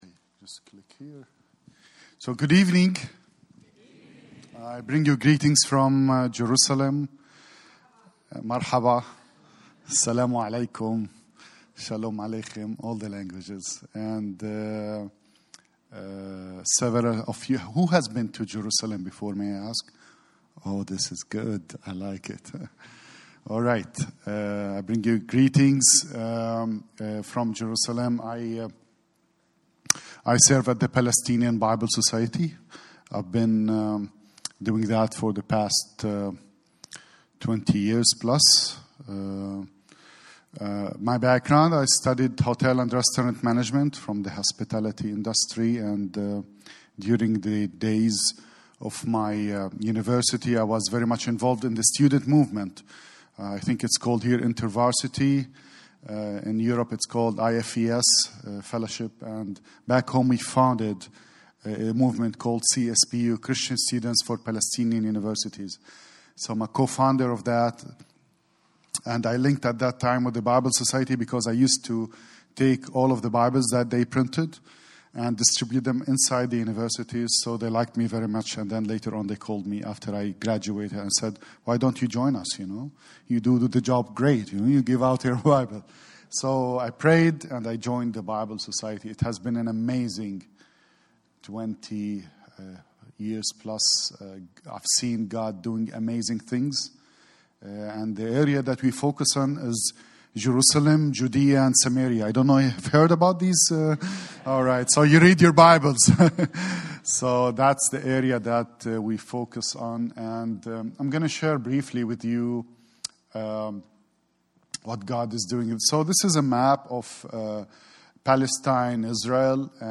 Speaker: Guest Preacher | Series: Missions Conference
Service Type: Special Events